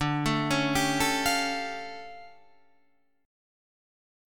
D Minor Major 11th